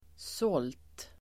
Uttal: [sål:t]